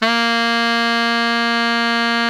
BARI  FF A#2.wav